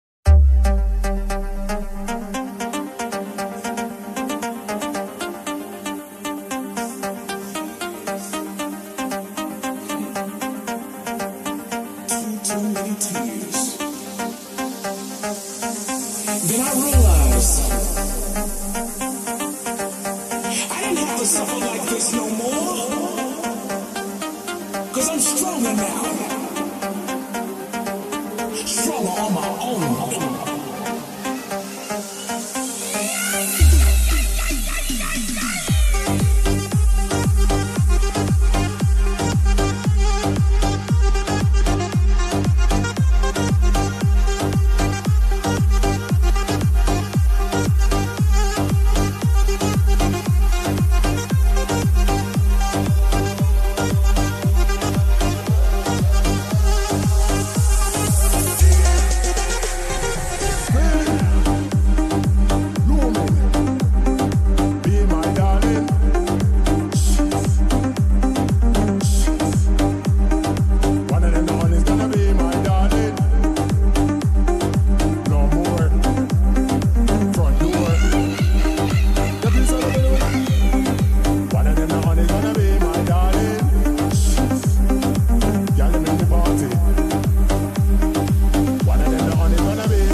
new Irani song bass boosted slowed reverb remix